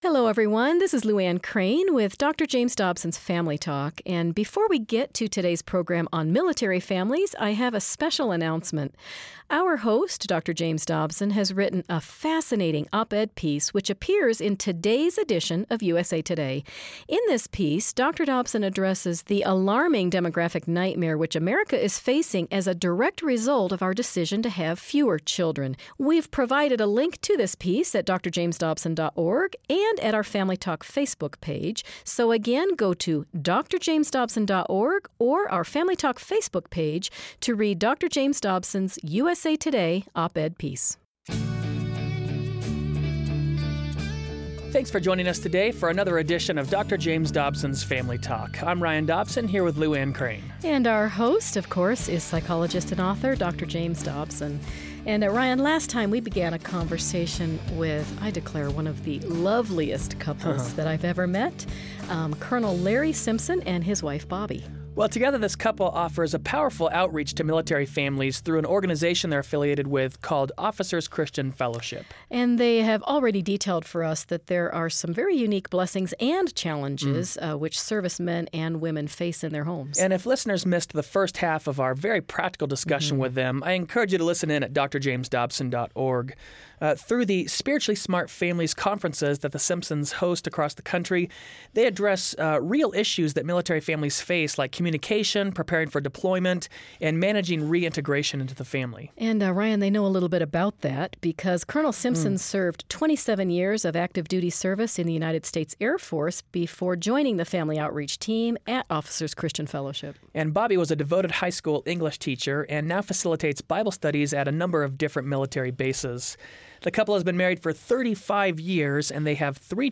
Between deployments, reintegration, and maybe even PTSD, the challenges AND the blessings are many. You'll hear from a couple who has walked this journey and has wisdom to share with others that are on the same path.